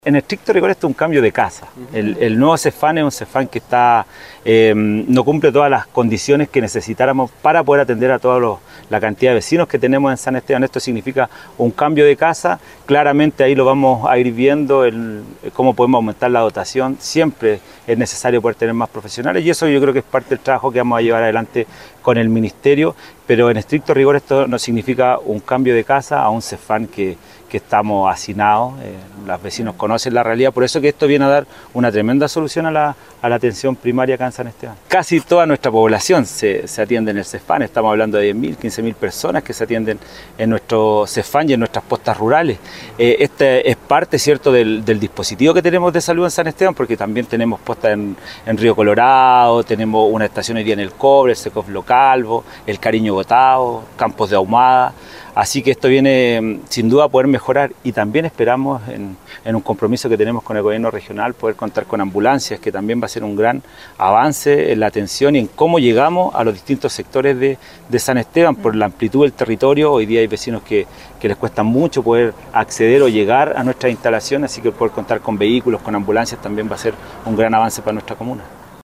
Por esta razón, el Alcalde Christian Ortega realizó un orgulloso análisis.